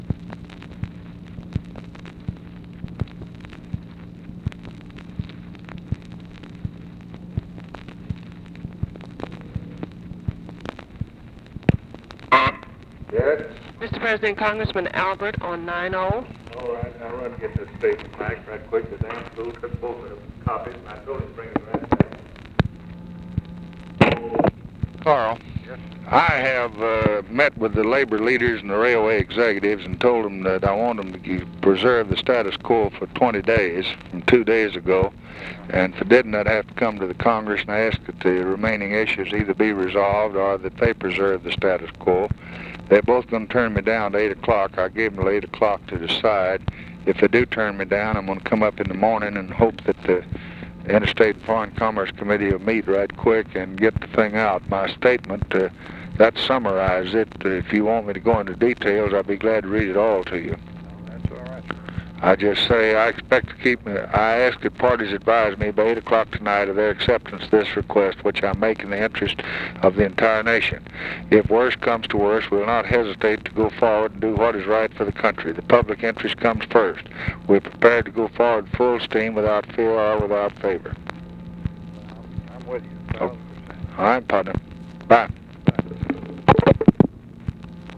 Conversation with CARL ALBERT and OFFICE CONVERSATION, April 10, 1964
Secret White House Tapes